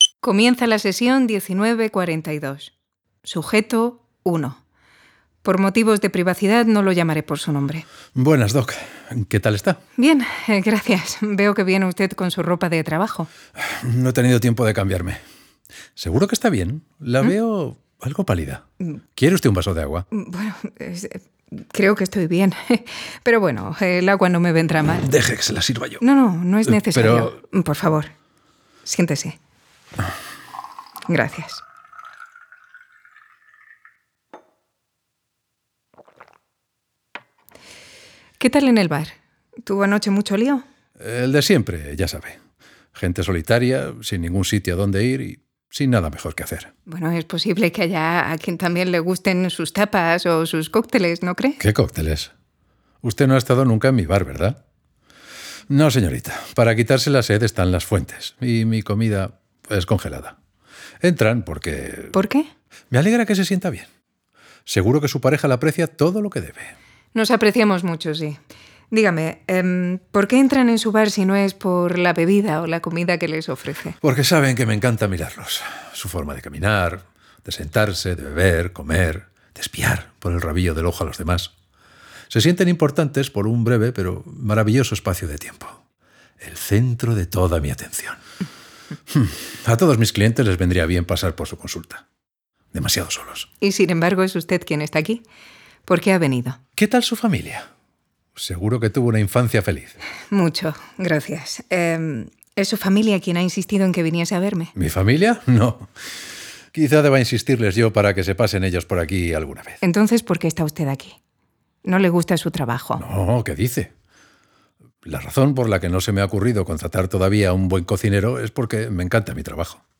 Format: Audio Drama
Voices: Full cast
Soundscape: Voices only